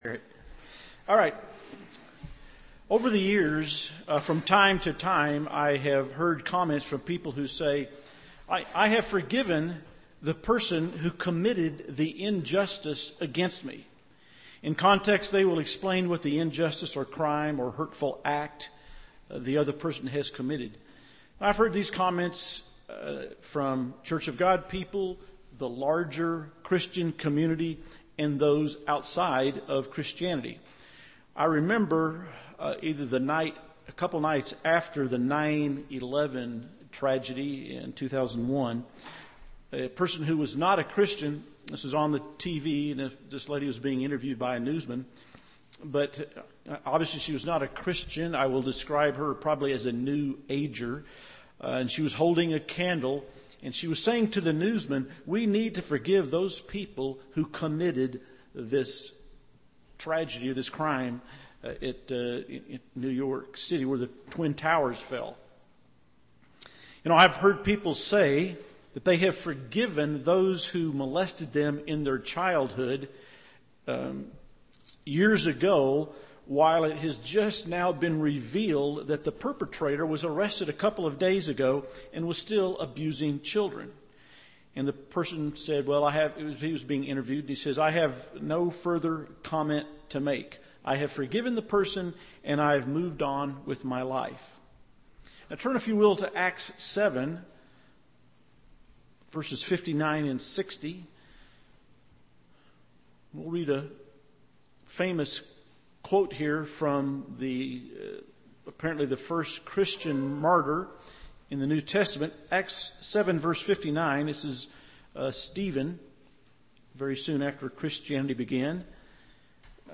Given in Loveland, CO